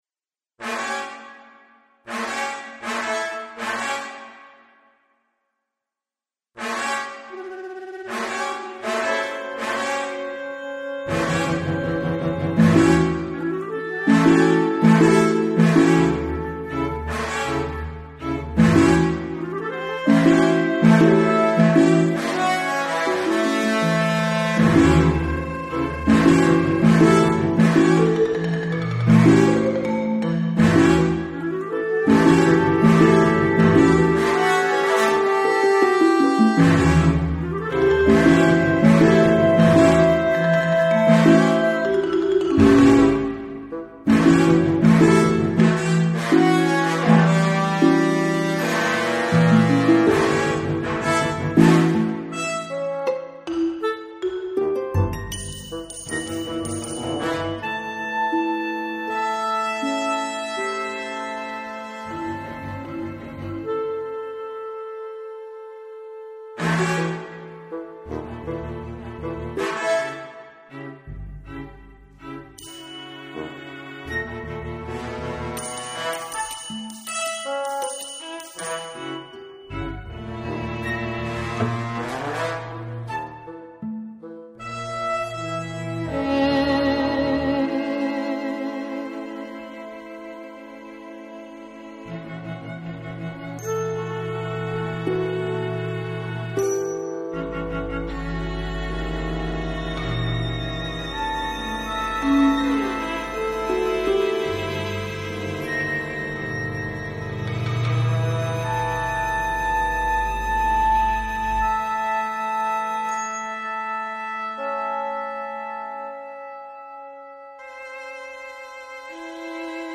16 for 12 in 5 Scored for: fl., cl., bsn., tpt., tbn., perc. (mrm., glock. & snare), hrp., vln. 1, vln. 2, vla., vc., cb.